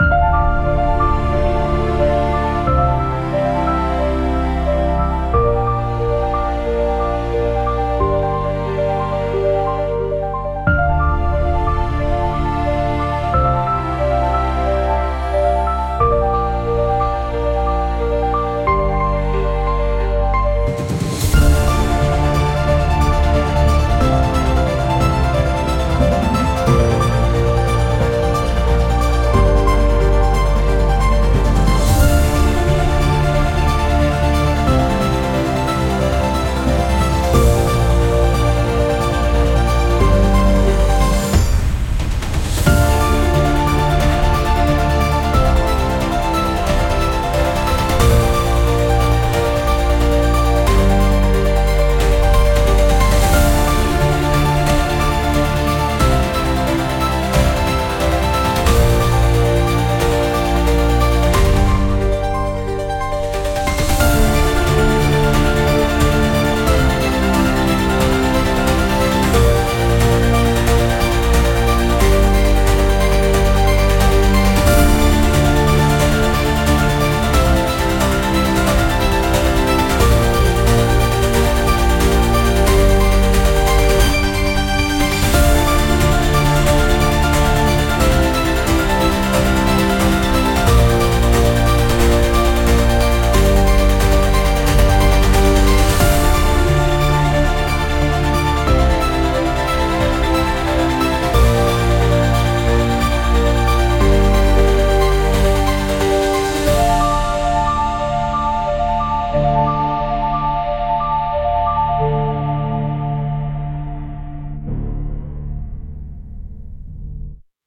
Instrumental _Breaking the Chain_ Join PeerTube Real Liberty Media -avoid Rumble 1.57.mp3